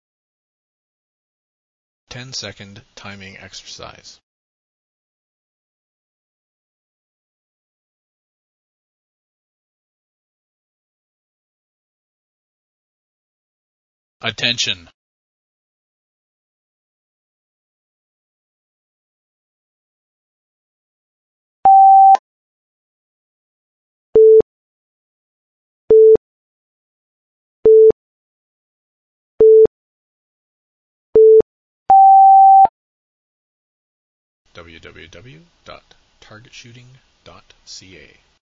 Känn rytmen, åk sedan till mullvaden och försk hålla den rytmen i skjutningen.
Första pipet = tavlorna vänder fram.
Följande pip = skott.
Sista utdragna pipet = tavlorn abort.